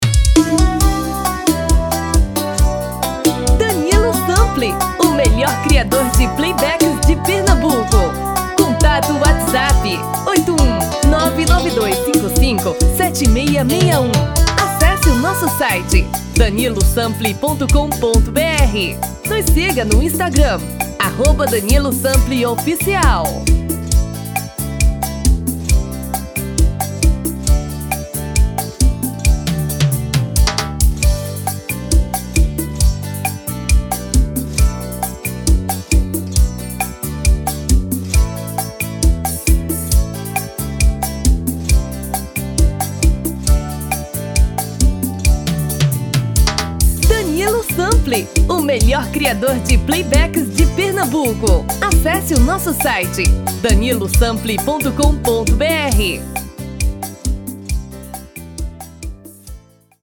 TIPO: Pot-Pourri de 4 músicas sequenciadas
RITMO: Arrocha / Seresta
TOM: Feminino (Original)